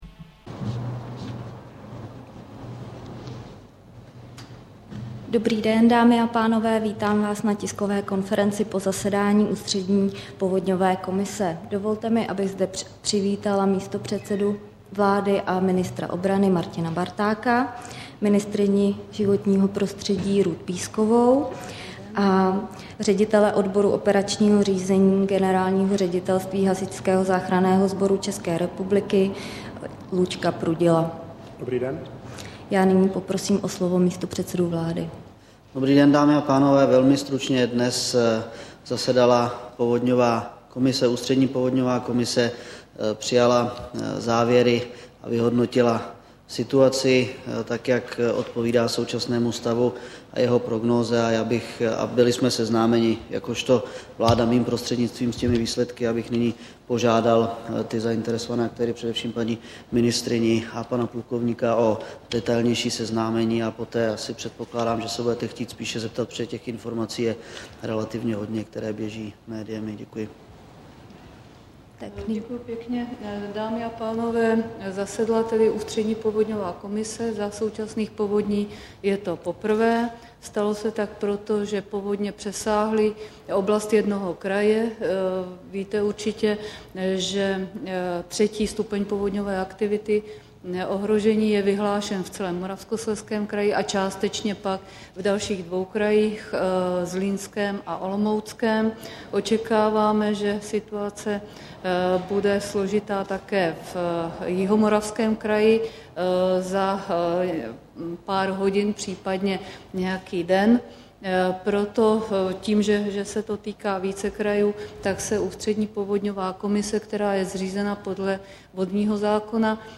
Tisková konference po jednání Ústřední povodňové komise, 18. května 2010